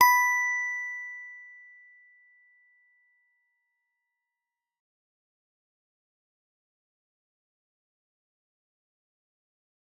G_Musicbox-B5-f.wav